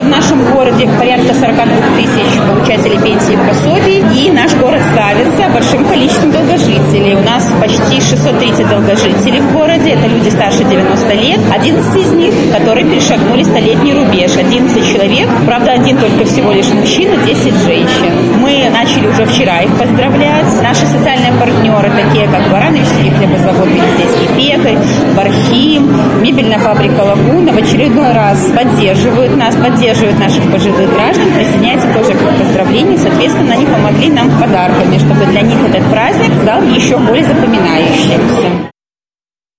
В Барановичах в День пожилых людей в городском Доме культуры состоялся большой праздник.
Такие мероприятия — уникальная возможность дать почувствовать людям серебряного возраста свою востребованность в обществе, — отметила начальник управления по труду, занятости и социальной защите Барановичского горисполкома Татьяна Вашко.